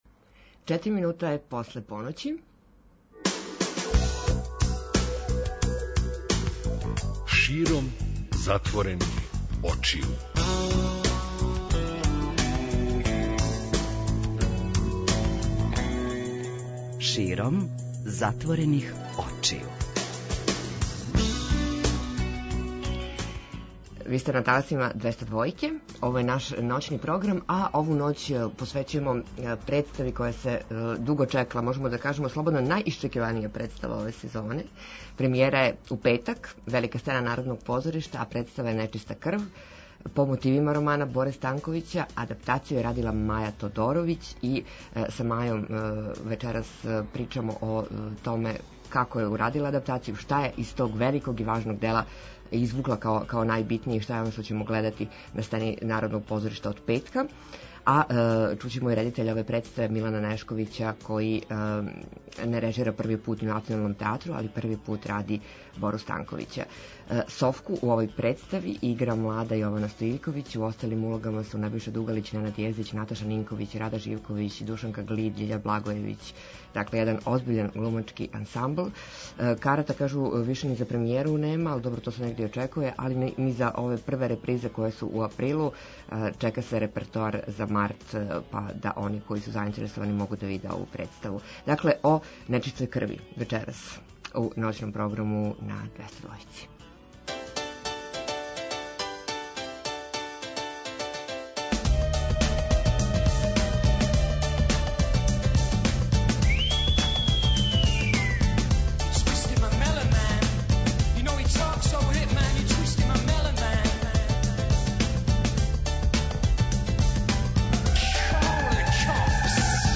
Гости: глумци и ауторска екипа представе „Нечиста крв”